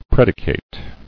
[pred·i·cate]